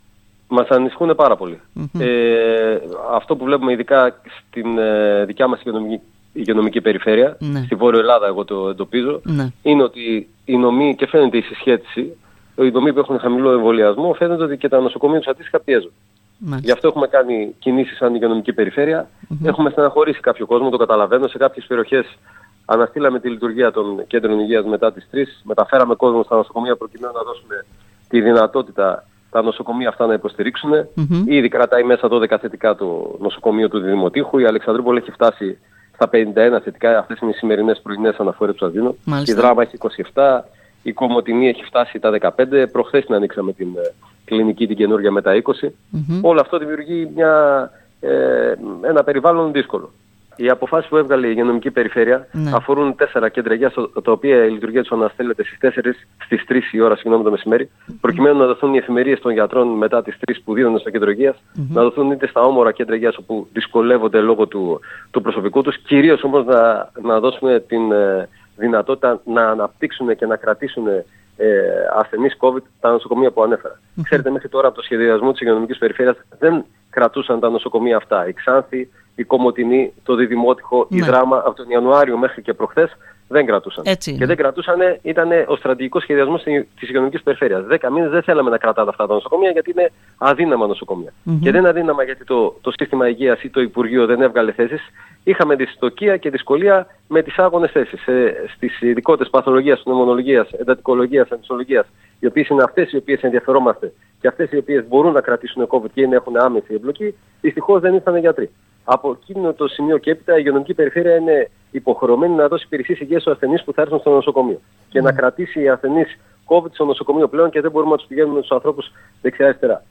Σε μια συνέντευξη -εξομολόγηση, προέβη σήμερα ο διοικητής της 4ης ΥΠΕ Μακεδονίας -Θράκης Δημήτρης Τσαλικάκης μιλώντας στην ΕΡΤ Ορεστιάδας.